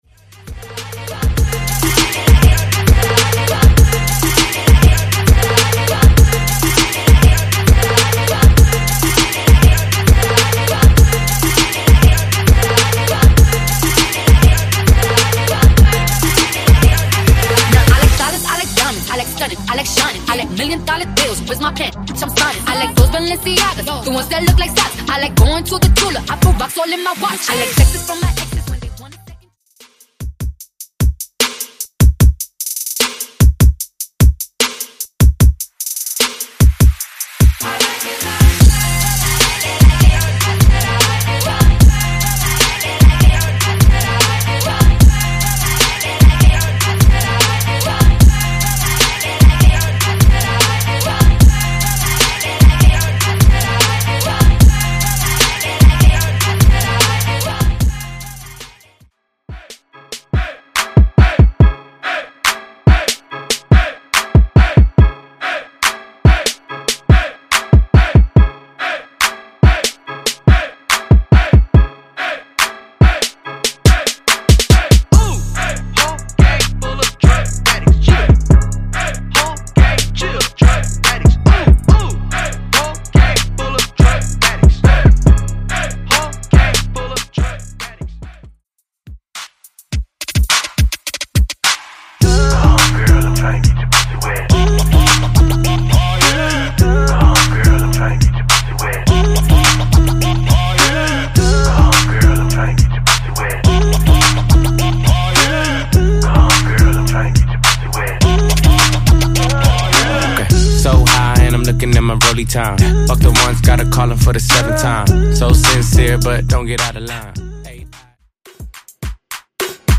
BPM: 105 Time